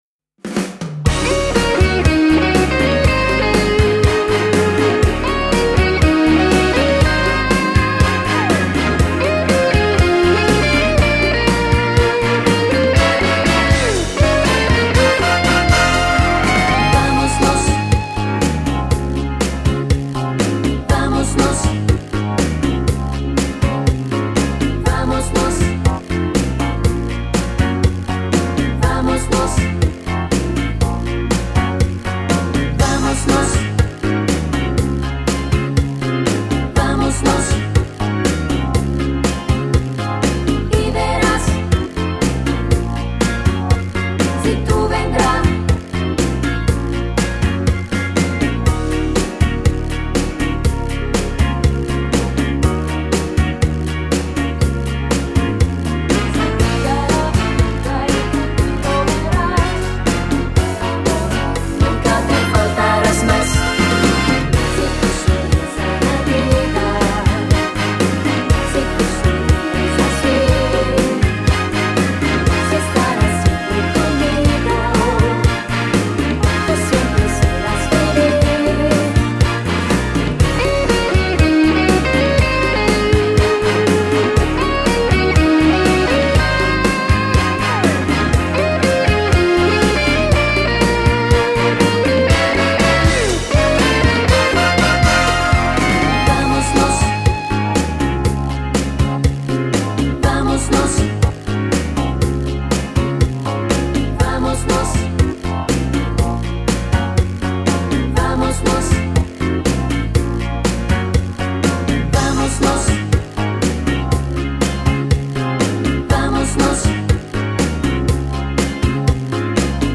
Genere: Cha cha cha